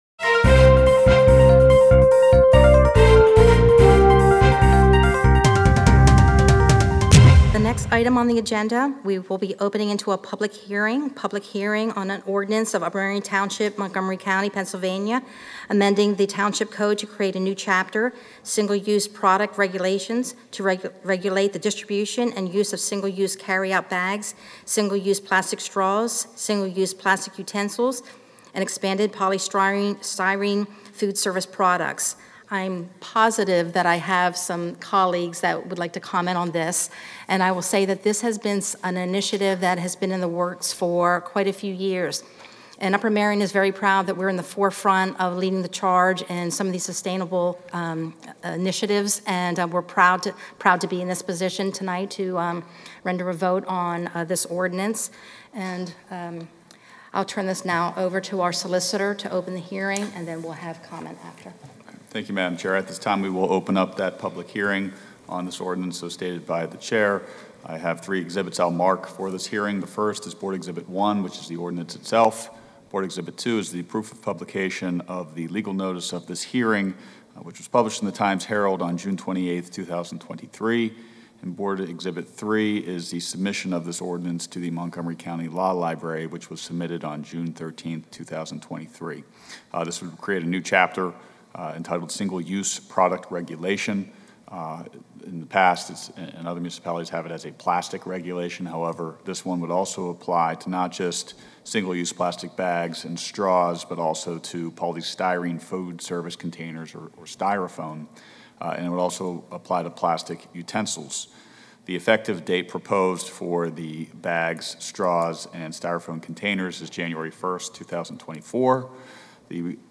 A short excerpt from the July 13, 2023 Upper Merion Board of Supervisors Meeting.